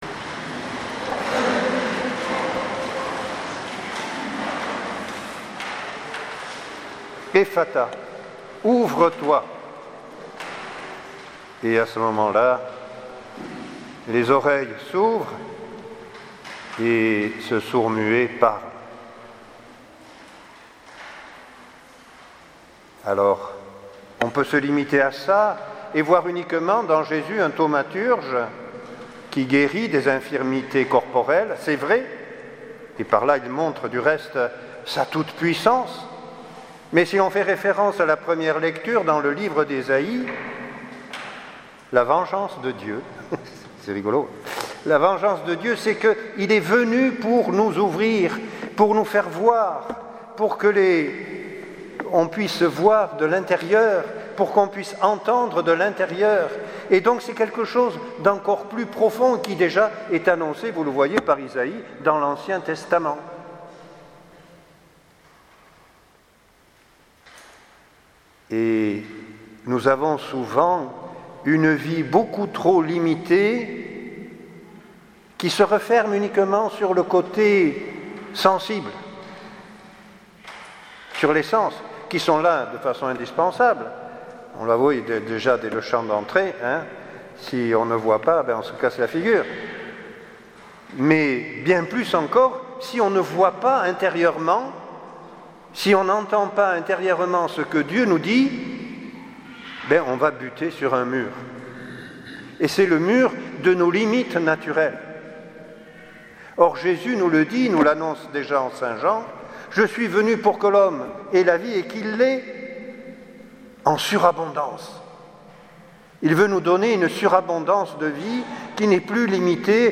Homélie